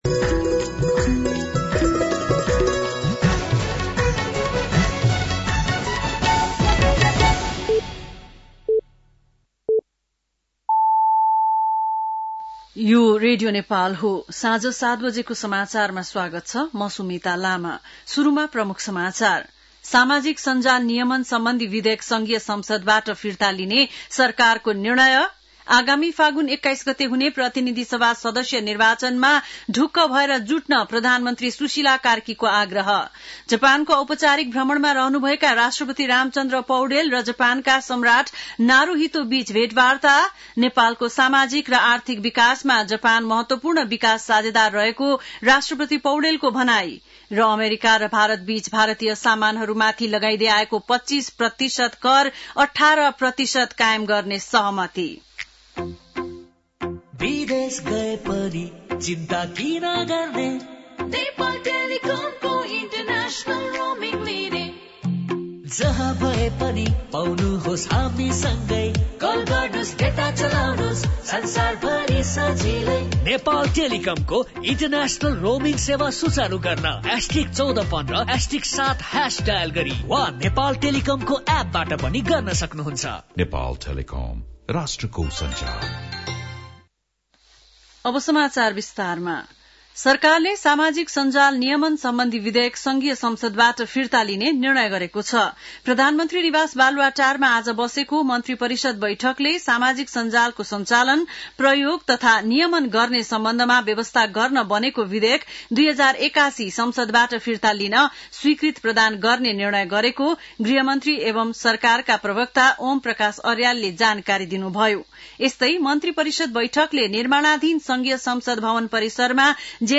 बेलुकी ७ बजेको नेपाली समाचार : २० माघ , २०८२